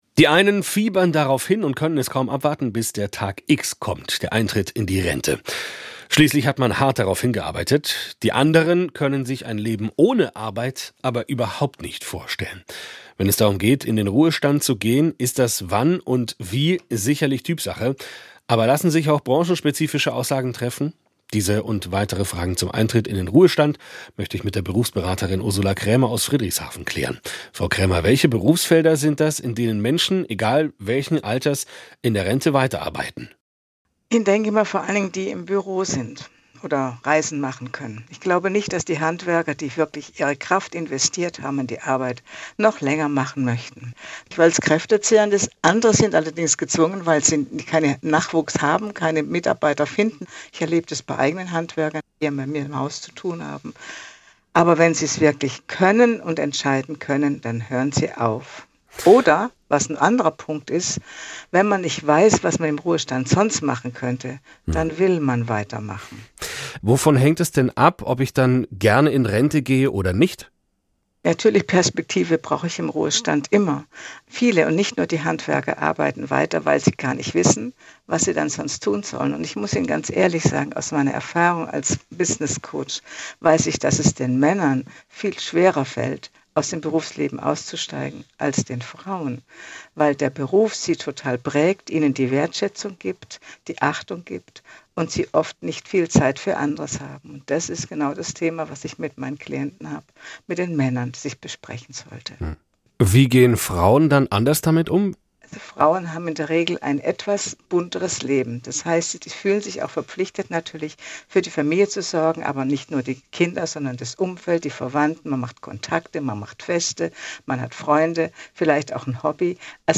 Berufsberaterin